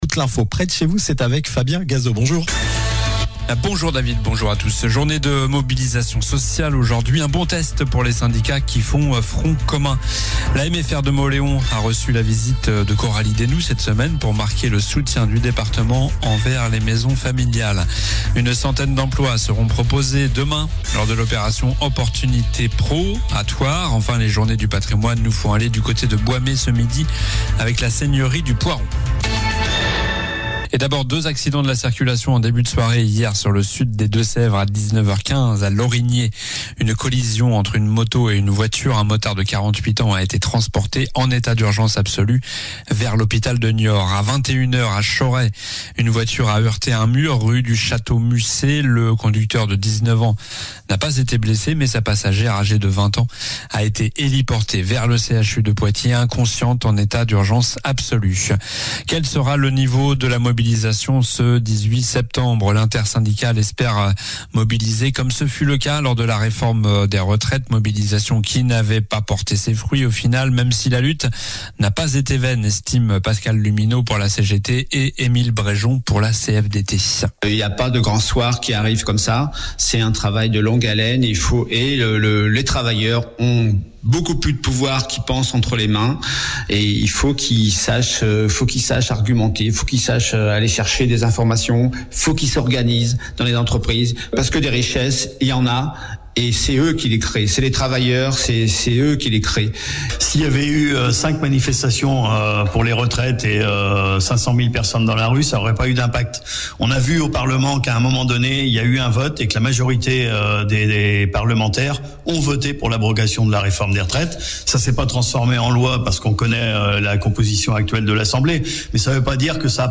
Journal du jeudi 18 septembre (midi)